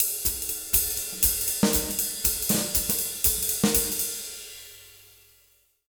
240SWING03-R.wav